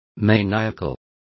Complete with pronunciation of the translation of maniacal.